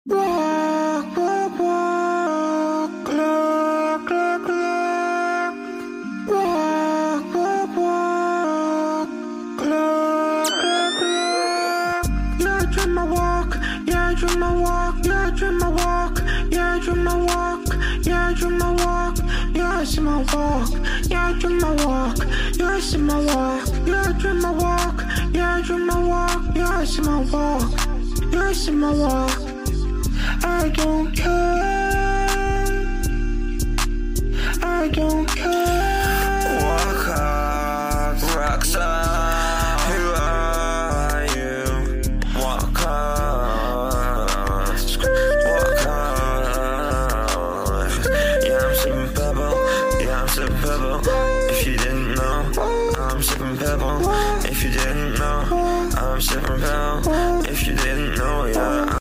it all hits hard.